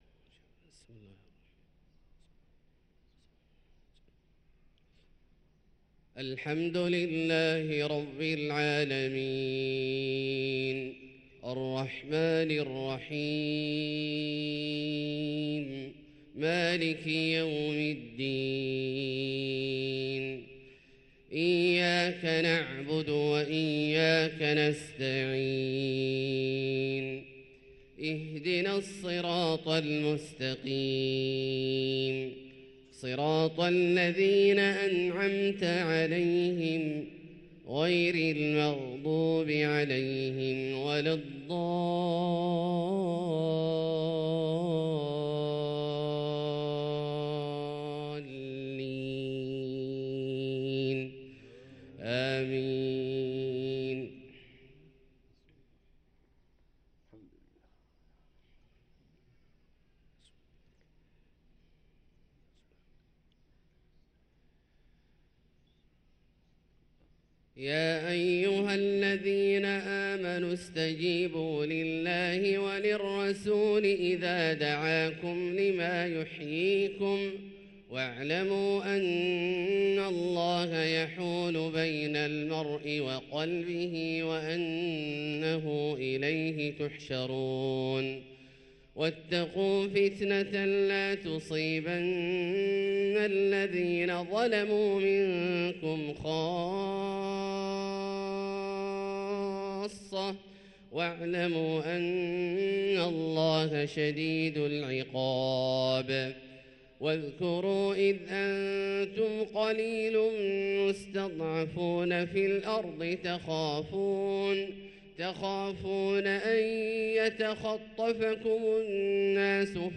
صلاة الفجر للقارئ عبدالله الجهني 2 ربيع الآخر 1445 هـ
تِلَاوَات الْحَرَمَيْن .